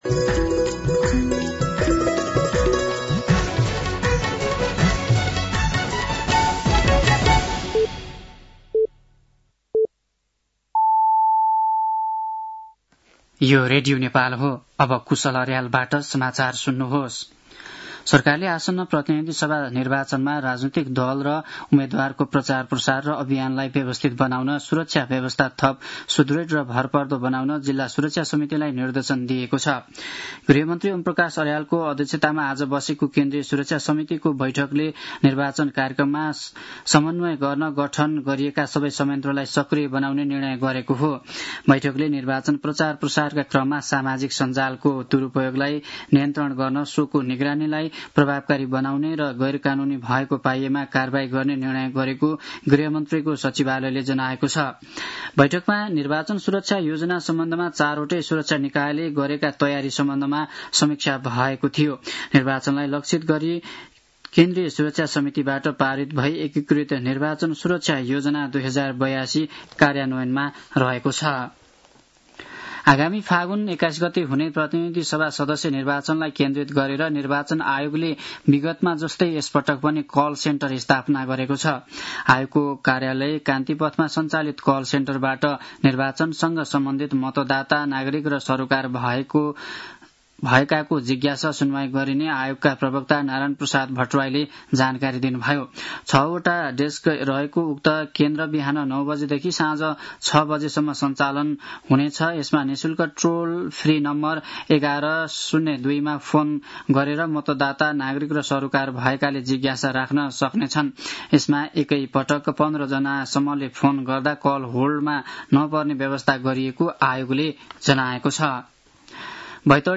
साँझ ५ बजेको नेपाली समाचार : ७ माघ , २०८२